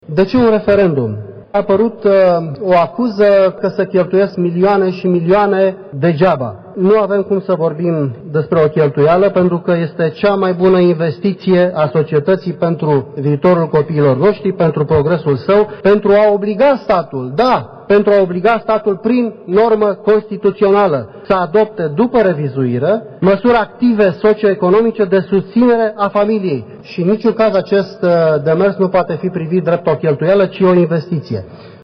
17sept-18-voce-VERIFICAT-Corlatean-de-ce-referendum.mp3